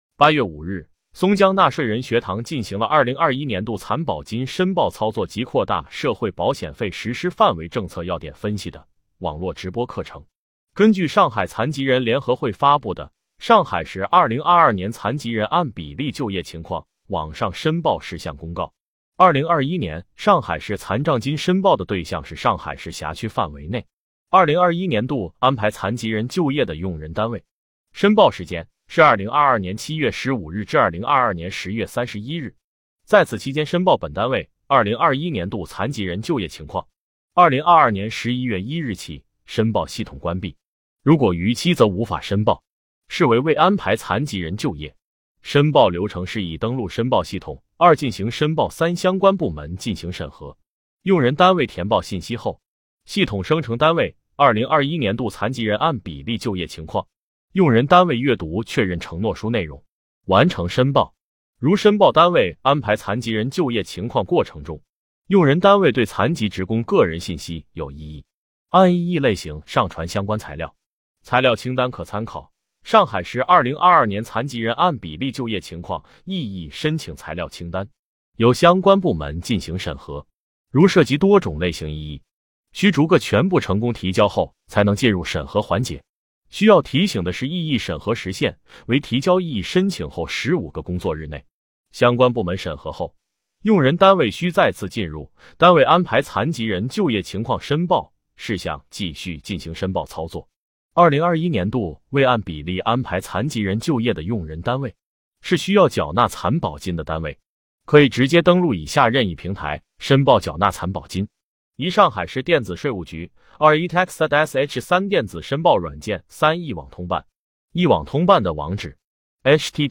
目前，松江区税务局通过网络直播的形式开展纳税人学堂。